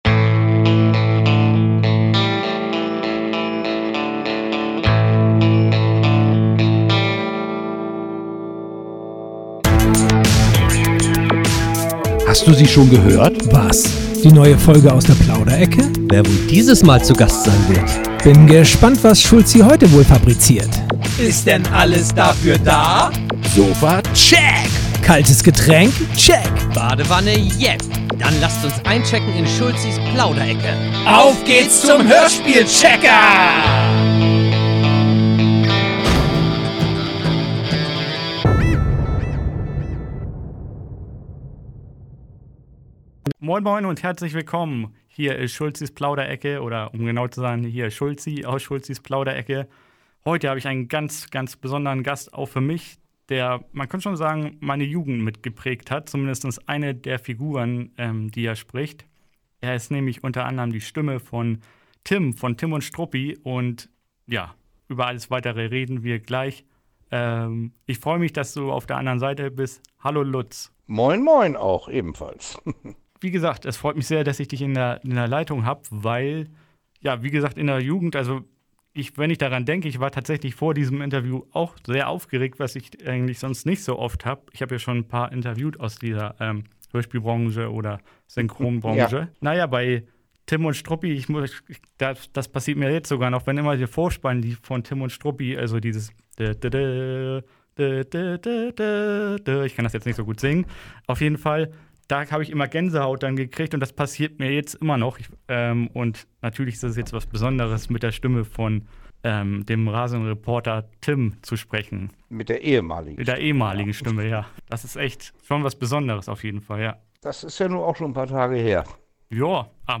Ihr erfahrt zum Beispiel in unserem Gespräch von ihm: Was es mit der Sache mit der Schulbehörde auf sich hat.